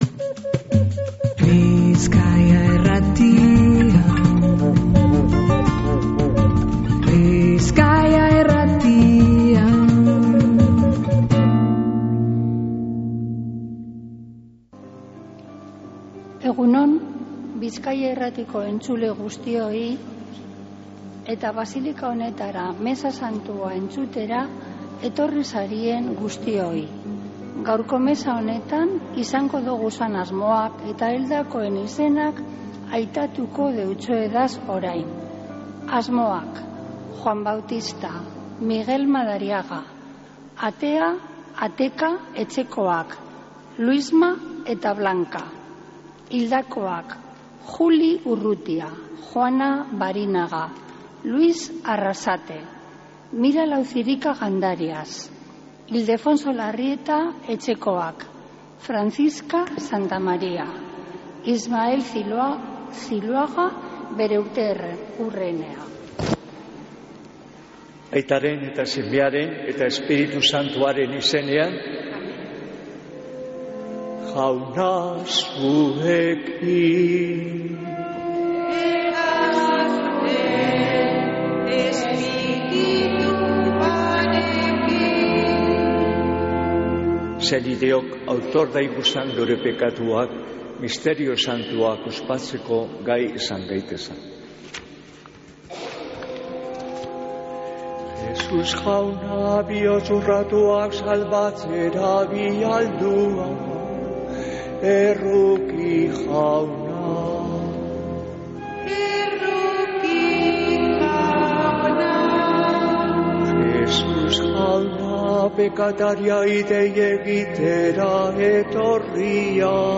Mezea (25-02-03)